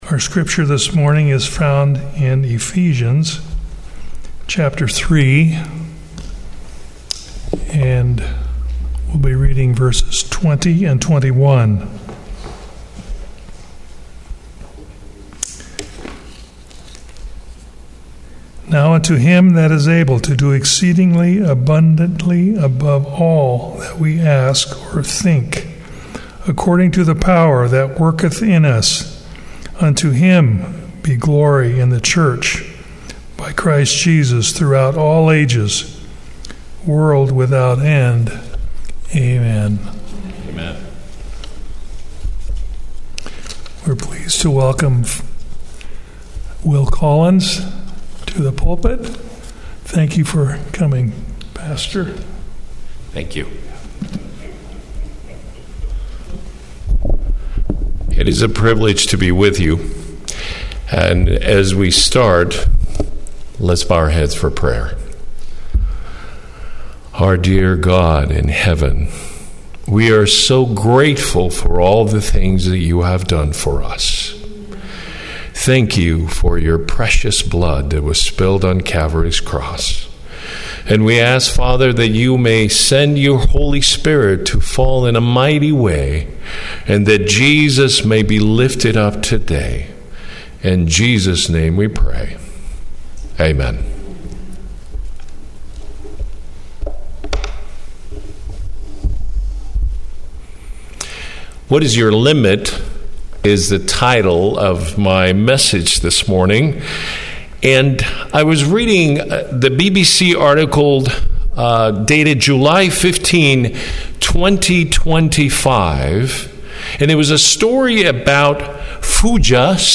Seventh-day Adventist Church, Sutherlin Oregon